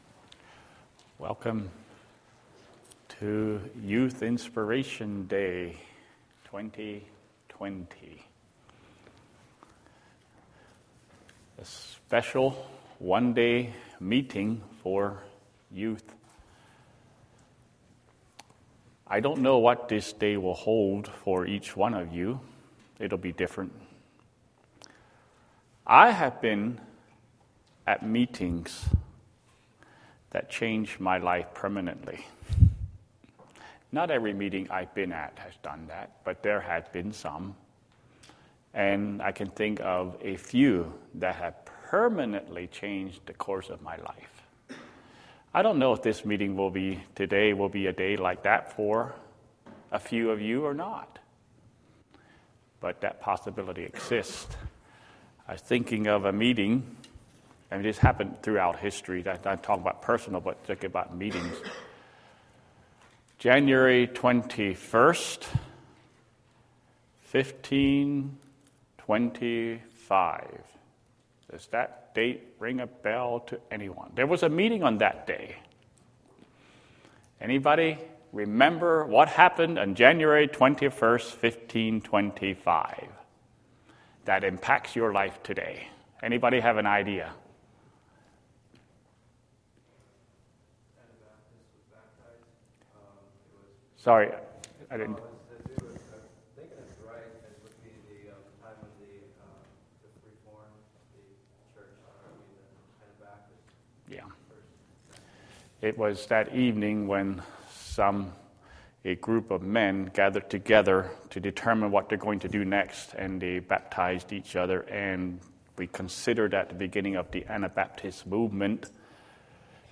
Series: Youth Inspiration Day 2020